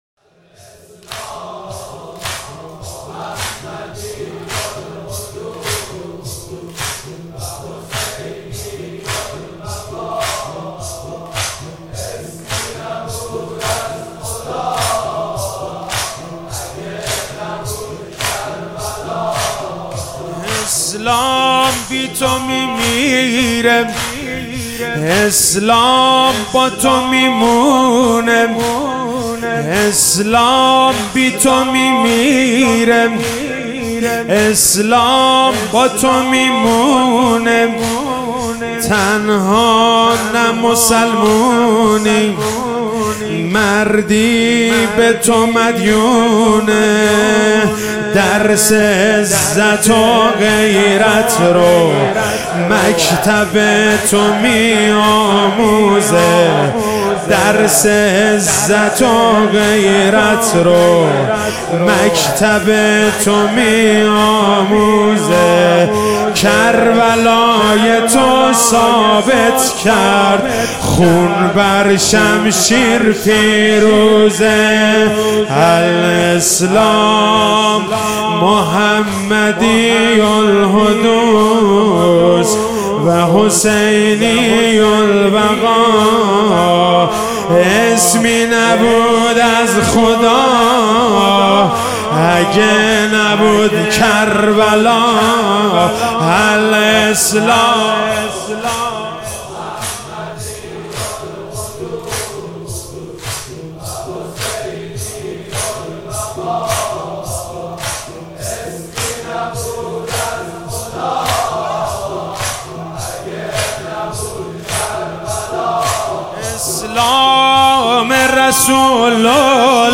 مداحی شب چهارم محرم 99